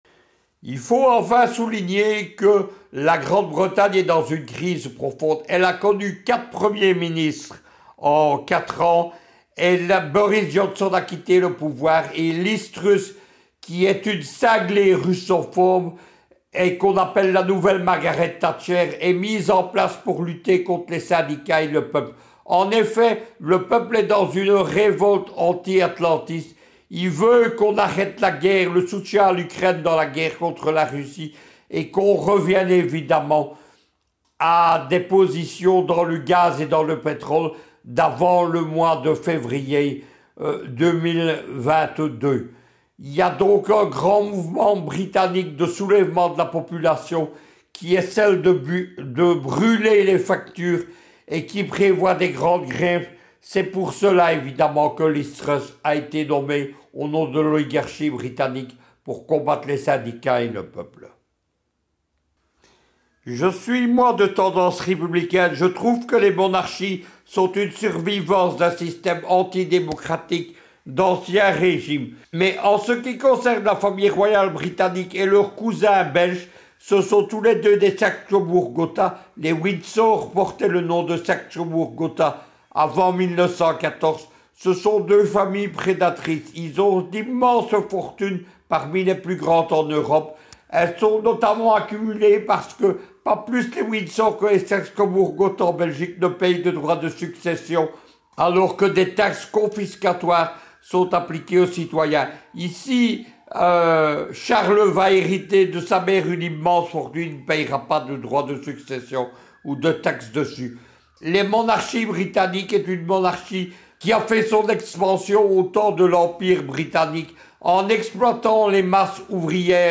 géopoliticien, intervient sur ce sujet.